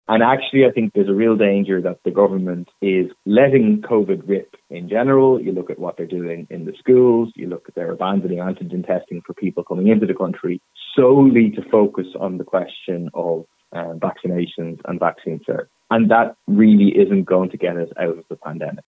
However People before Profit TD Paul Murphy feels the government isn’t doing enough: